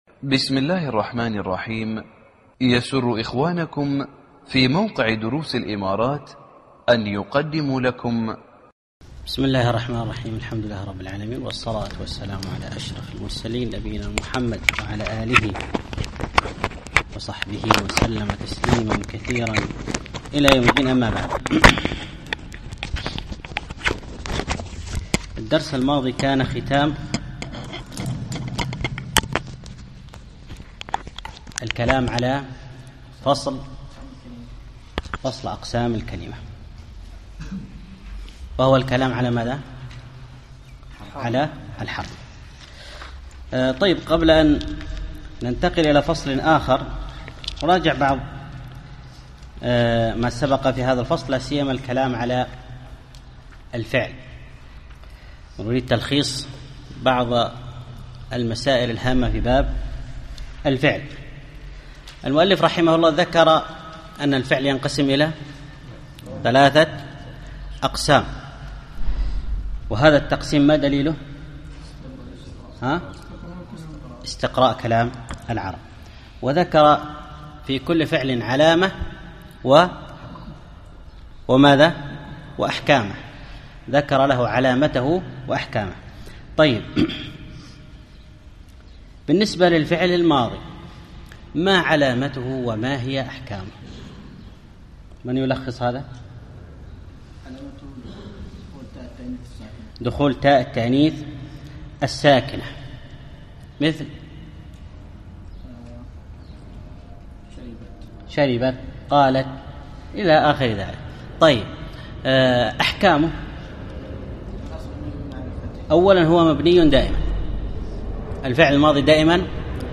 الدرس السابع